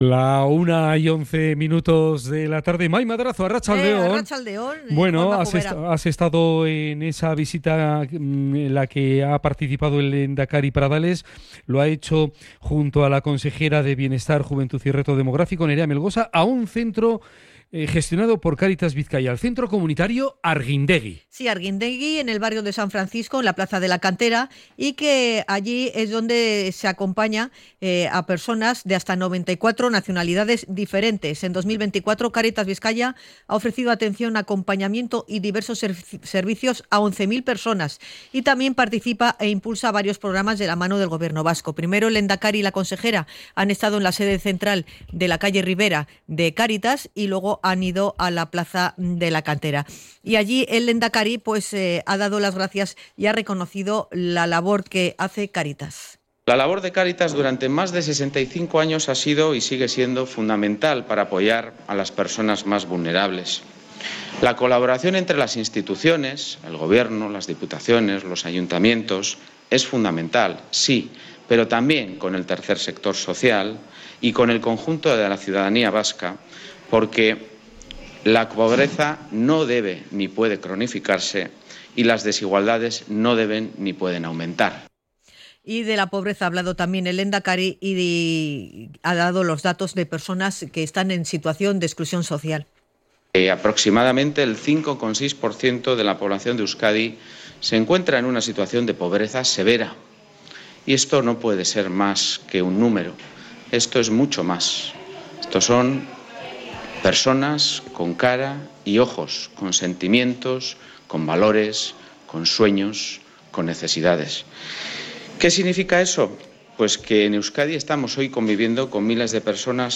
Pradales ha realizado esta declaraciones en Bilbao, tras visitar el Centro Comunitario Hargindegi gestionado por Cáritas Bizkaia, y después de que se haya hecho público que el Gobierno central y Canarias se han dado un plazo de diez días para cerrar este mismo mes de enero un texto para la distribución extraordinaria y puntual de menores extranjeros migrantes entre las comunidades autónomas.
VISITA-LEHENDAKARI-CARITAS.mp3